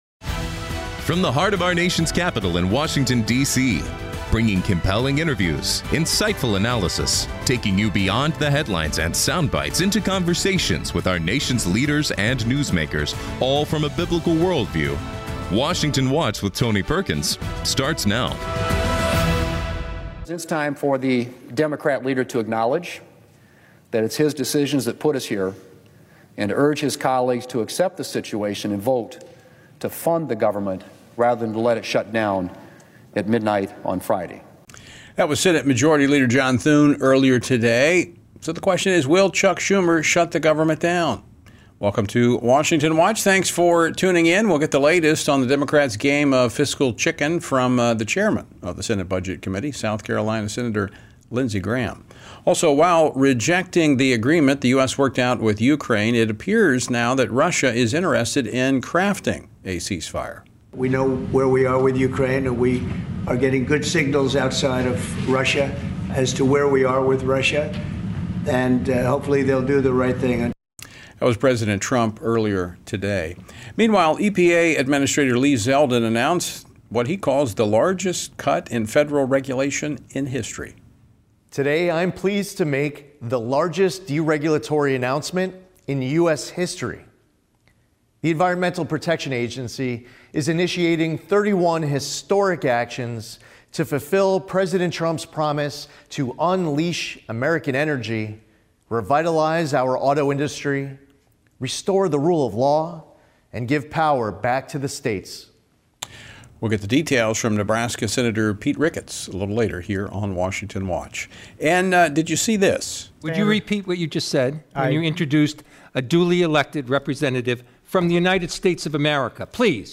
On today's program: Lindsey Graham, U.S. Senator from South Carolina, offers insight to the government shutdown fight, peace negotiations in the Russia-Ukraine war, and the ongoing violence in Syria. Pete Ricketts, U.S. Senator from Nebraska and Member of the Senate Environment and Public Works Committee, shares the latest on the deregulation efforts at the EPA.